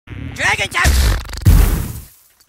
Dragon Shout Téléchargement d'Effet Sonore
Dragon Shout Bouton sonore